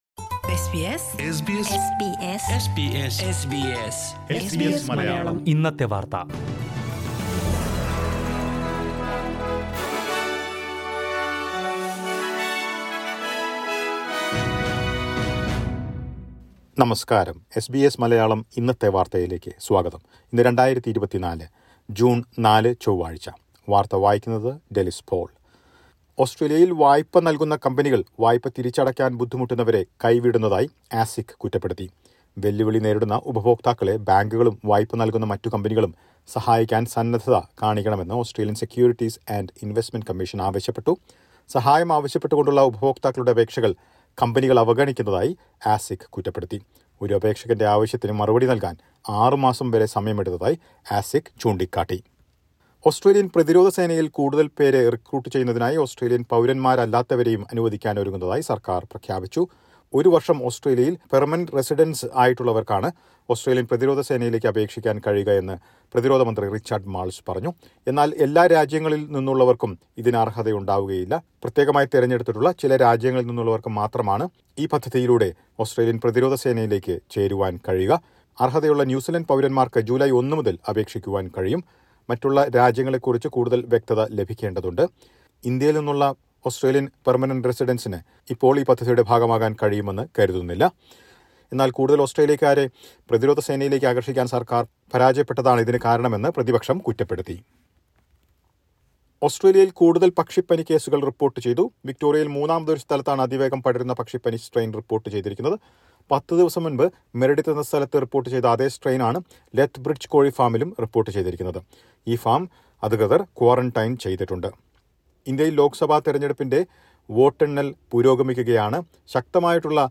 2024 ജൂൺ നാലിലെ ഓസ്‌ട്രേലിയയിലെ ഏറ്റവും പ്രധാന വാര്‍ത്തകള്‍ കേള്‍ക്കാം...